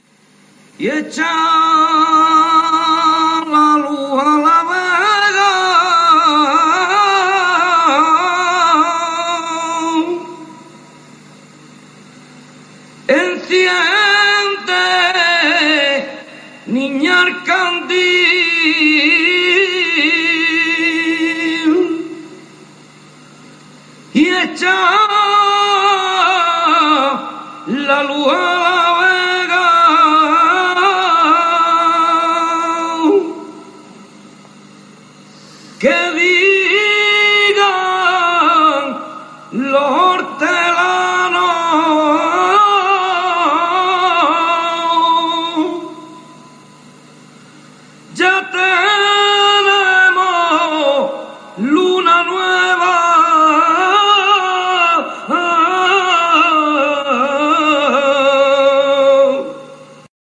II : CHANTS A CAPELLA
6) Chants de travail ruraux